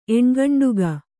♪ eṇgaṇḍuga